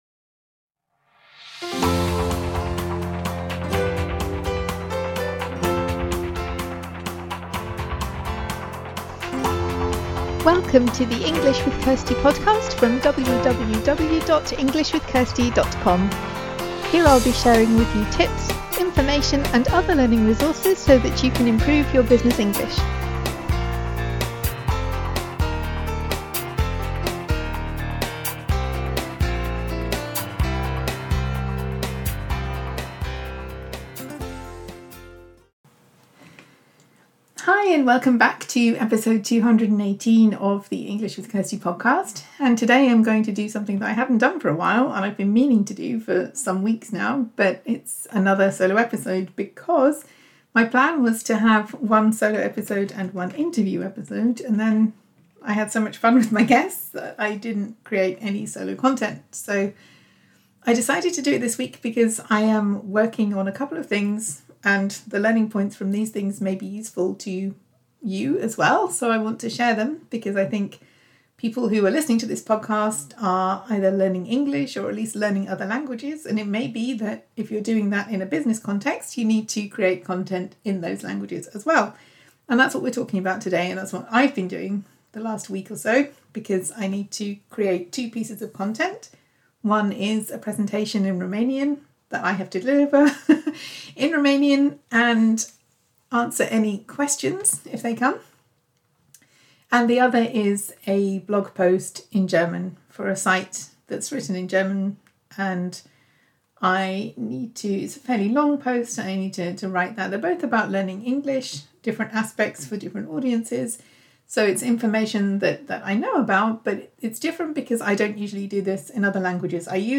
I haven’t done a solo episode for a while, so I decided to talk about producing content in other languages.